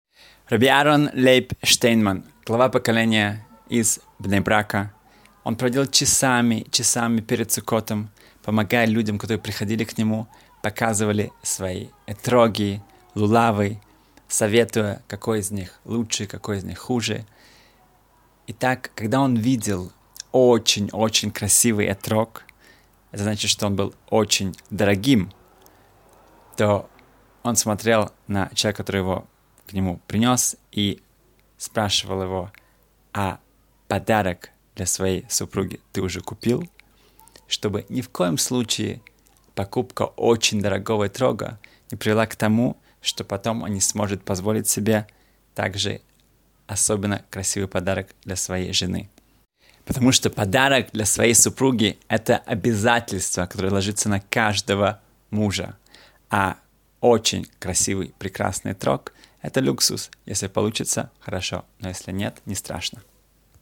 Не забыть о приоритетах — слушать лекции раввинов онлайн | Еврейские аудиоуроки по теме «Суббота» на Толдот.ру